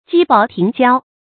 激薄停浇 jī bó tíng jiāo
激薄停浇发音